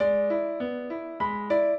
minuet4-9.wav